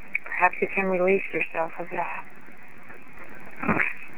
A soft questioning EVP response replied, “Release?”
EVP's